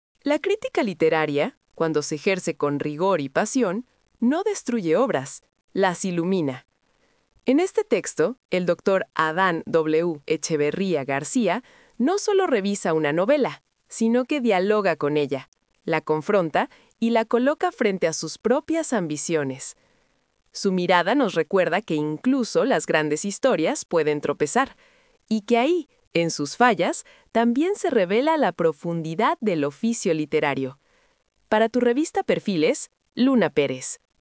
🎙 COMENTARIO EDITORIAL
abejerio.wav